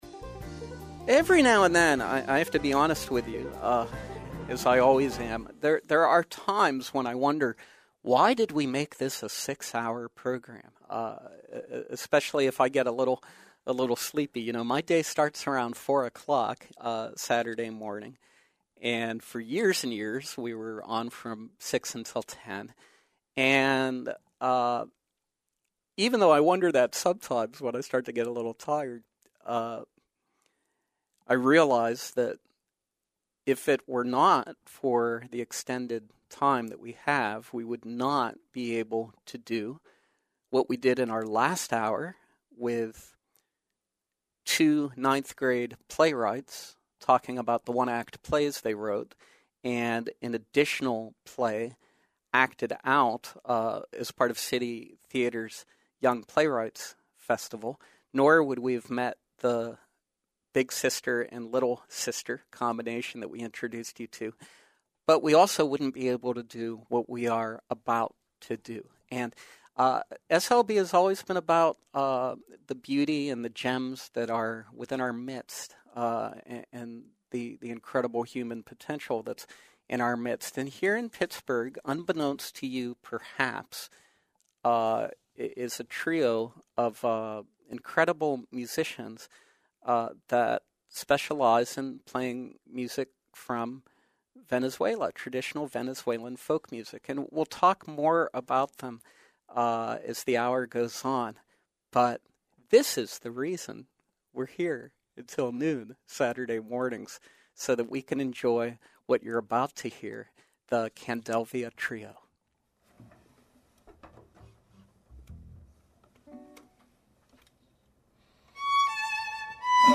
whose specialize in traditional Venezuelan folk music.
Performance